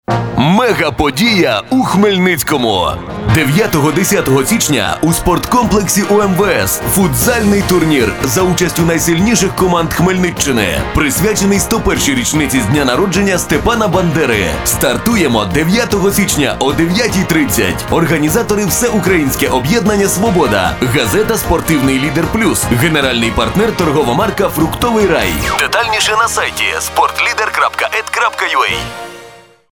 Реклама турніру